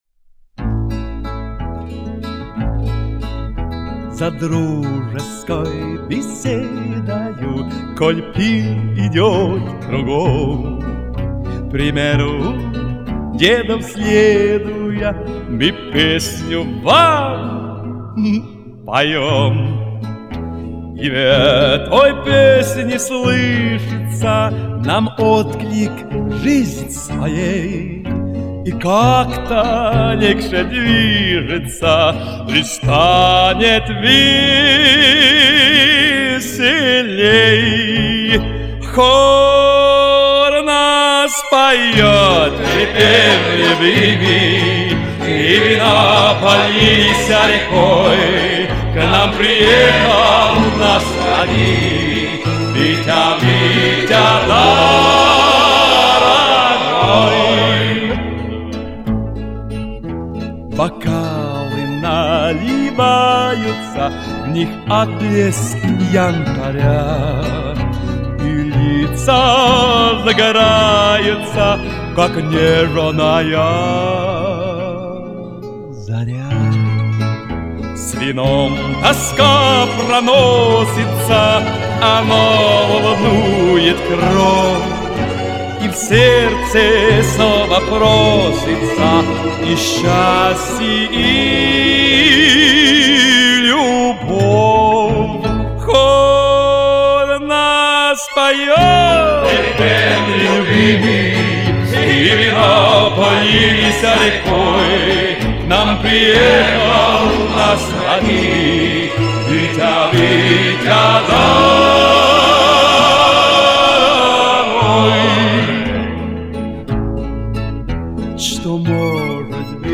стандартная цыганская :)